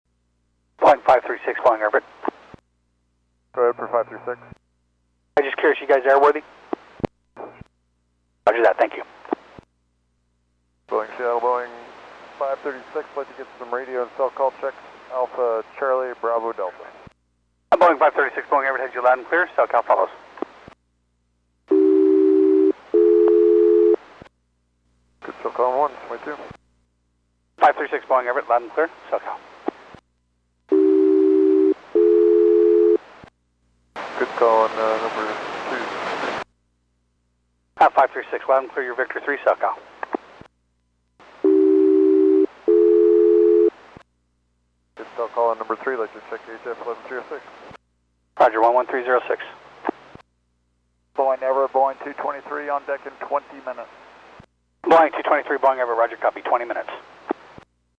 ZB536 Vietnam Airlines 787-9 VN-A861 taxi test before a